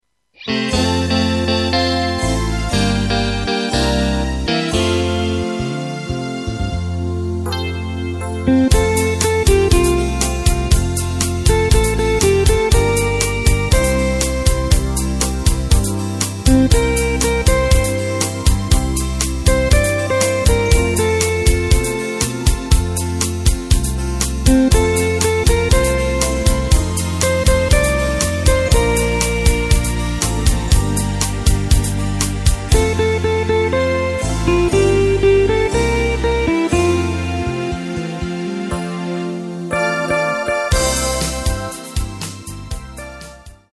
Takt:          4/4
Tempo:         120.00
Tonart:            F
Schlager aus dem Jahr 1994!